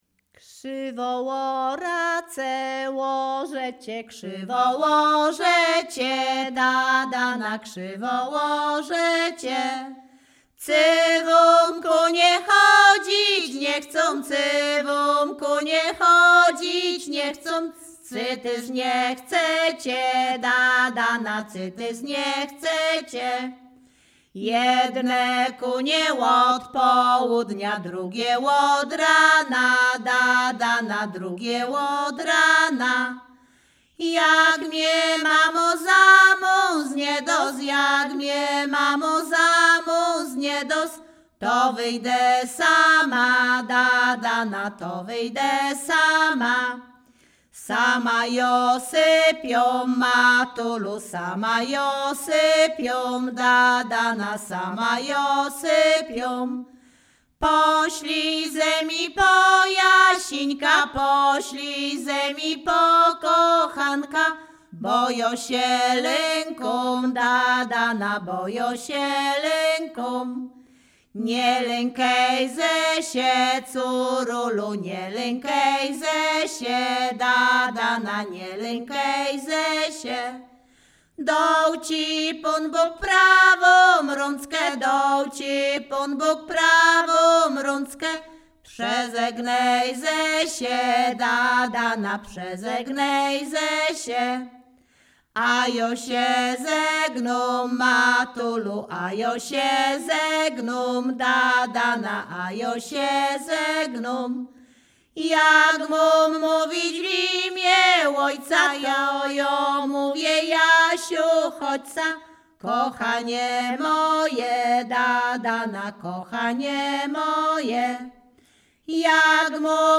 Śpiewaczki z Chojnego
województwo łódzkie, powiat sieradzki, gmina Sieradz, wieś Chojne
liryczne miłosne żartobliwe